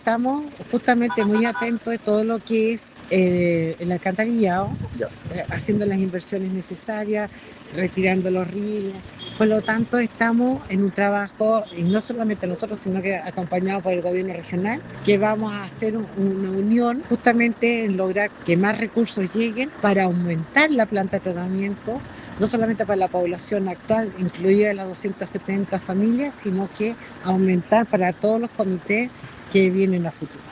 La alcaldesa María Elena Ojeda señaló que esto fue superado, pero que se analiza aumentar los arranques con fondos del Gobierno Regional para afrontar la población que aumenta.